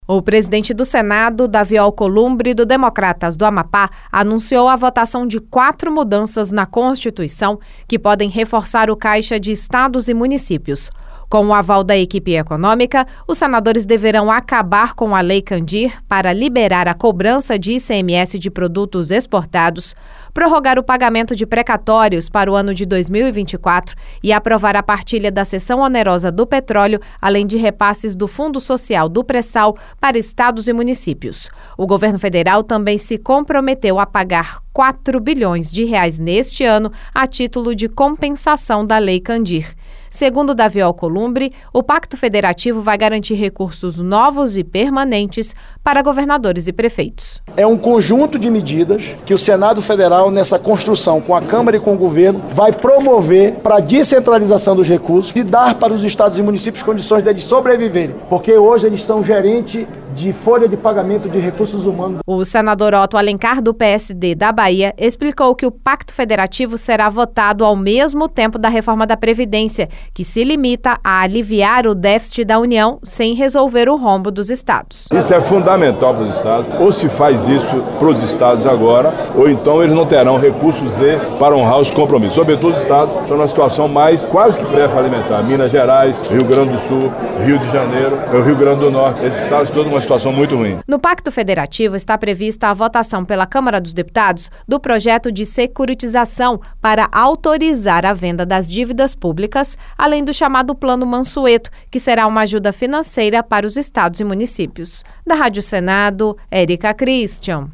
O senador Otto Alencar (PSD-BA) explicou que o Pacto Federativo será votado ao mesmo tempo em que a Reforma da Previdência.